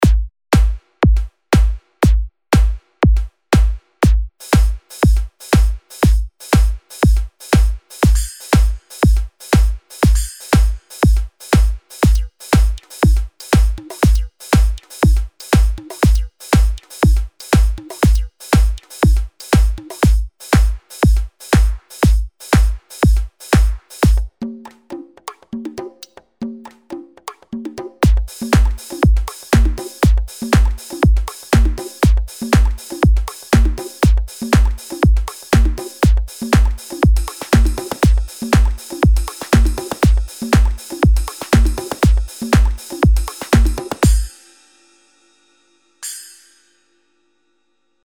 Fertige Dance-Presets
Viele der Dance-Presets sind sehr straight; teilweise trifft man auch auf Vorlagen, die kein vollständiges Paket aus Grooves liefern, sondern lediglich Entwürfe für eine eigene Ausarbeitung darstellen.